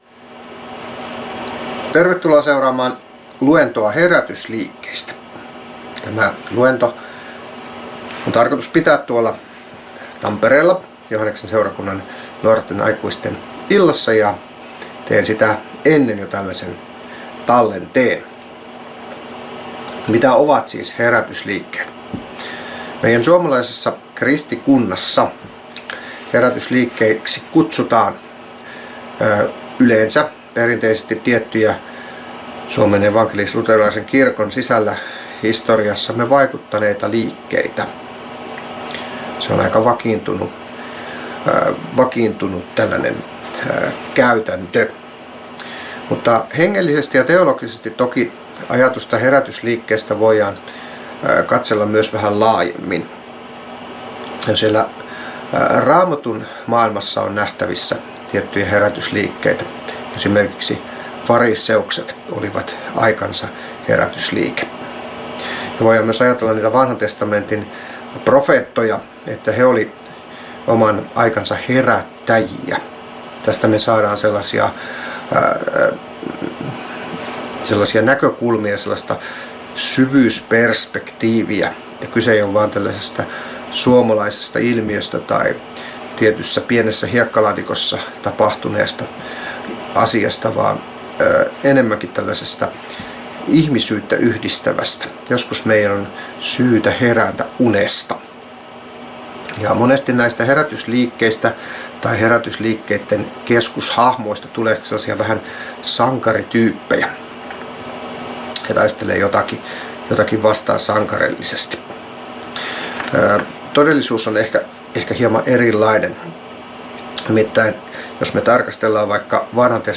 Lappeenranta